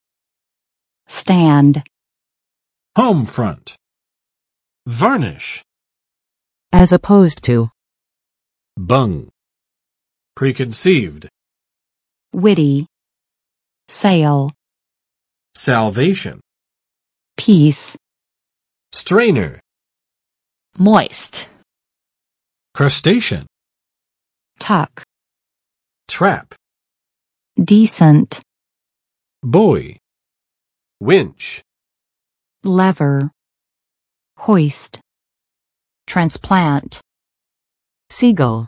[stænd] v. 处于某种状态
stand.mp3